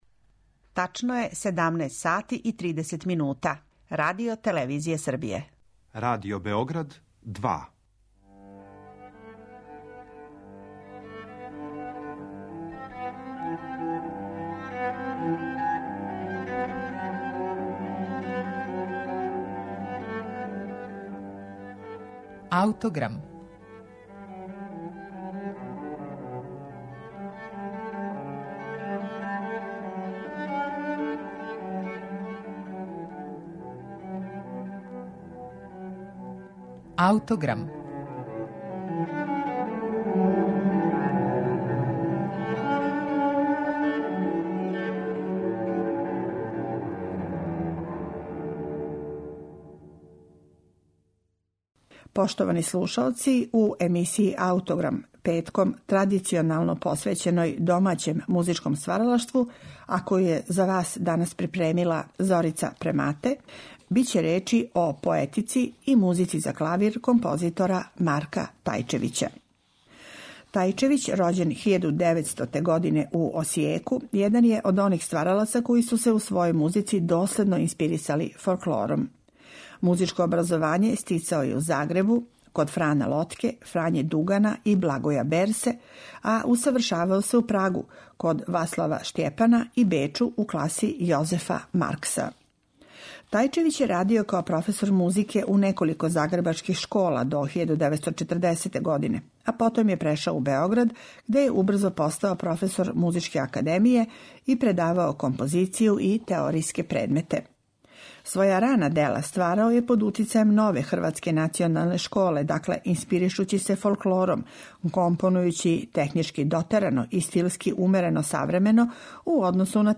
клавирске музике